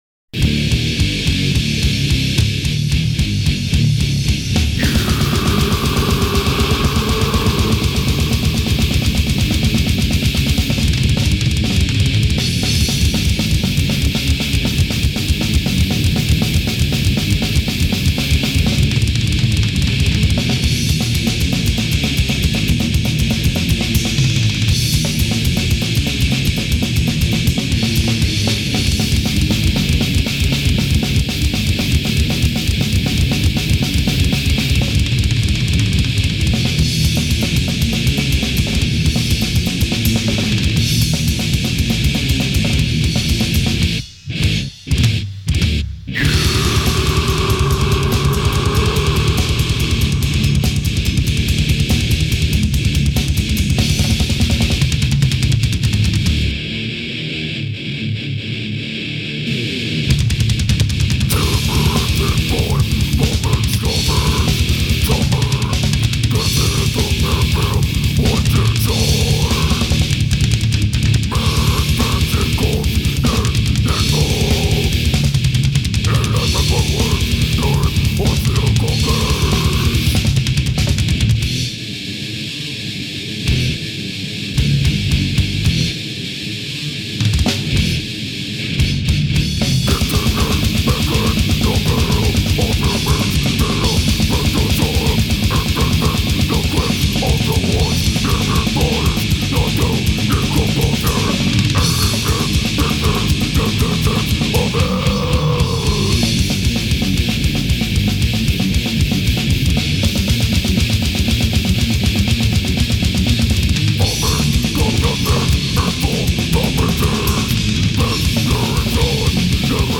>spams drums
>pig noises
>deedly deedle lee guitar